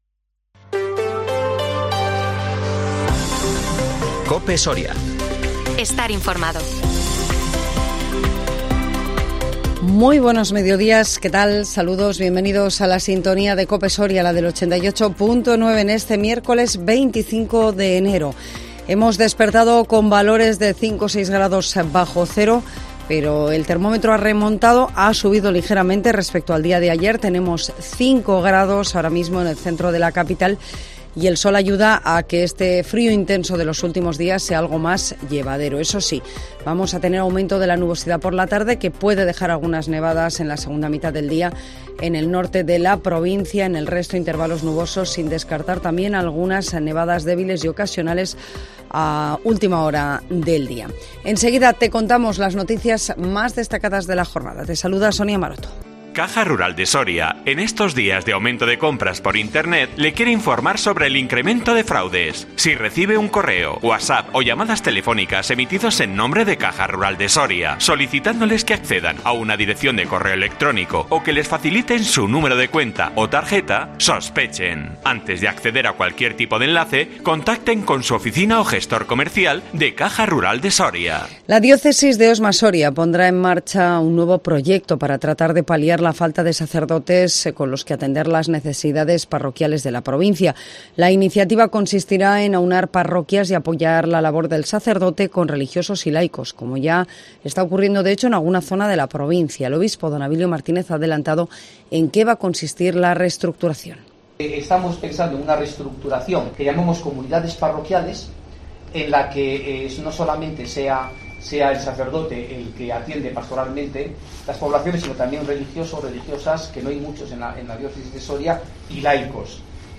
INFORMATIVO MEDIODÍA COPE SORIA 25 ENERO 2023